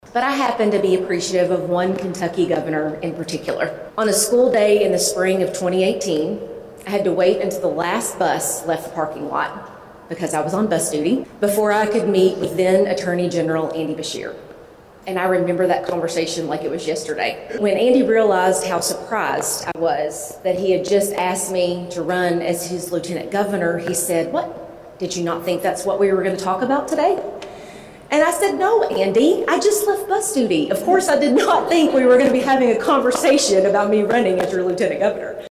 During a Monday morning press conference at the Kentucky History Center’s “Hall of Governors” in Frankfort, the 43-year-old from Mercer County announced her 2027 candidacy — a full year before the gubernatorial primary — while sending a clear message to the Commonwealth.